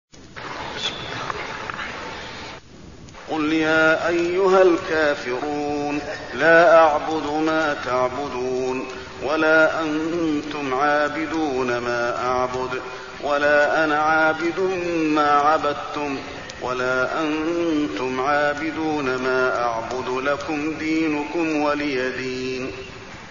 المكان: المسجد النبوي الكافرون